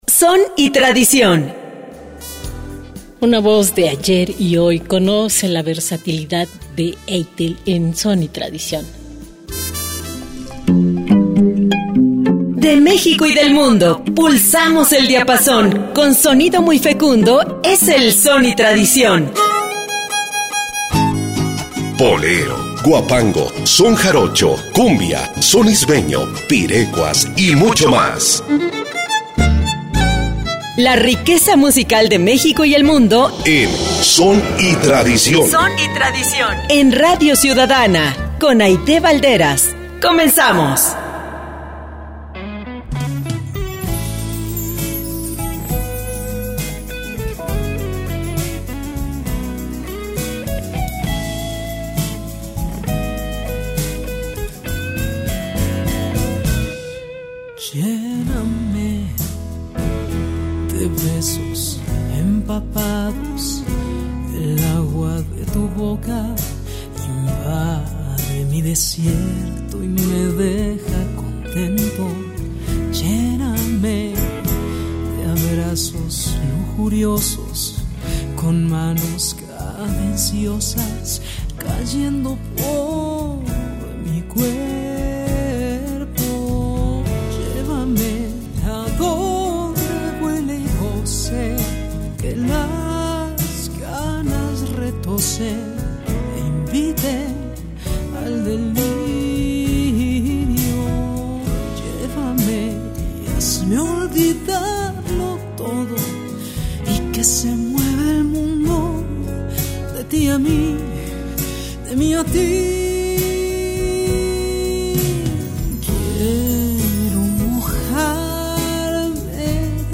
Son y Tradición es un espacio enfocado en difundir música tradicional de México y del mundo.